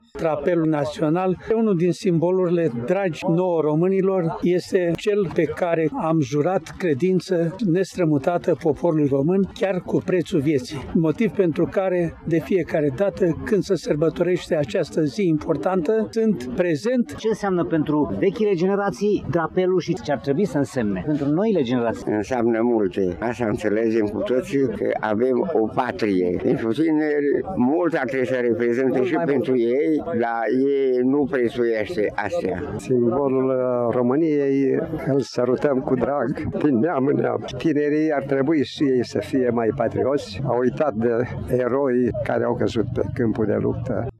Înafara oficialităților, militarilor și a preoților, puțini cetățeni s-au alăturat ceremoniei, iar oamenii și-ar fi dorit ca patriotismul să nu fie afișat doar cu prilejul festivităților: